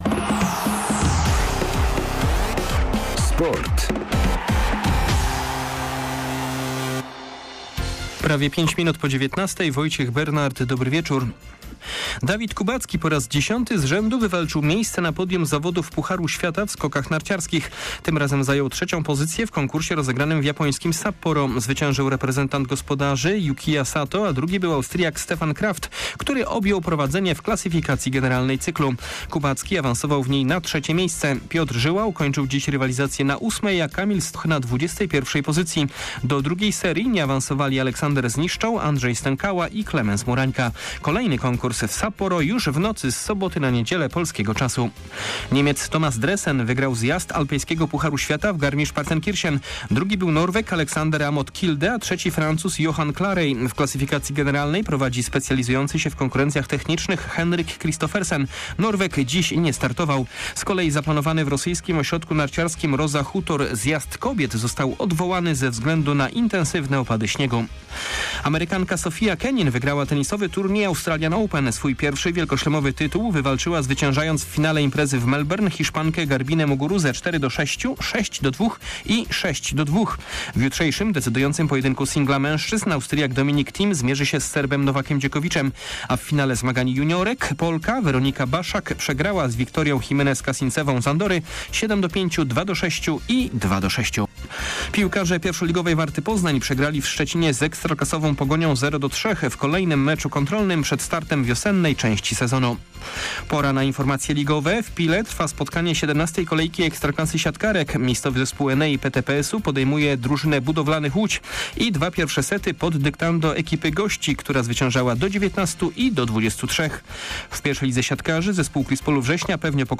01.02. SERWIS SPORTOWY GODZ. 19:05